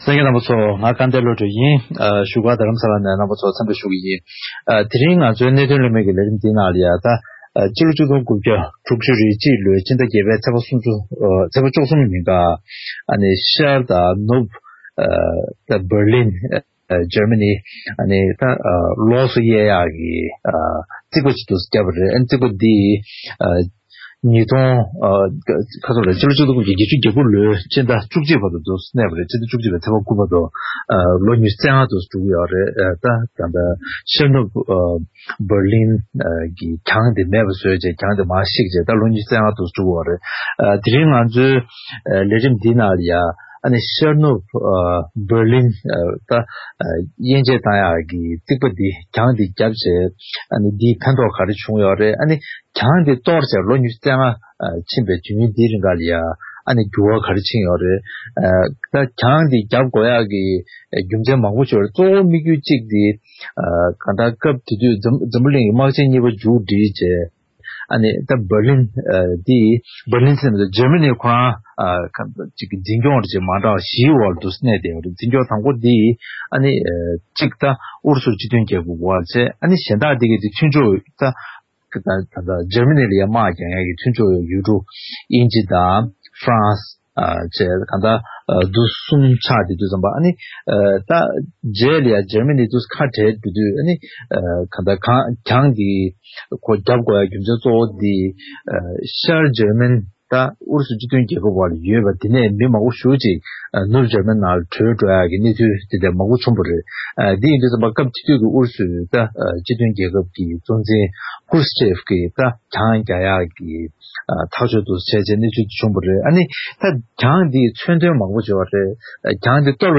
གླེང་མོལ